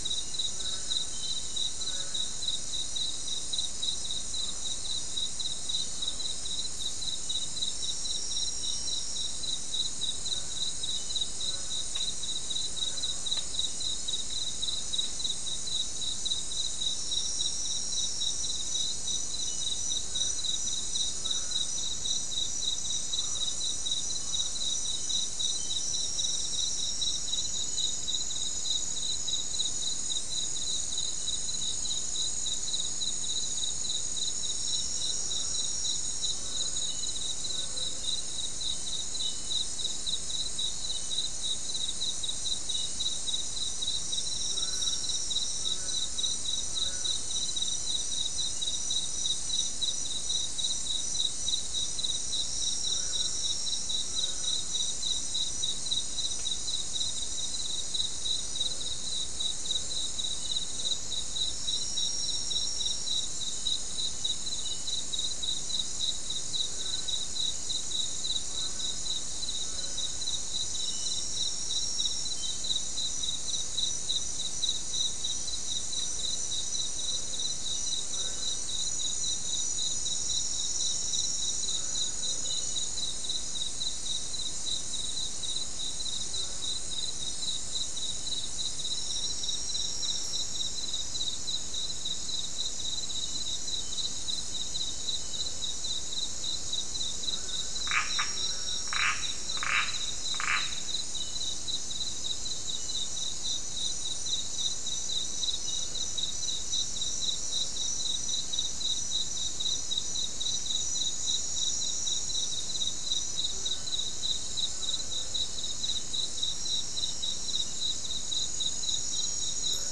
Non-specimen recording: Soundscape Recording Location: South America: Guyana: Mill Site: 3
Recorder: SM3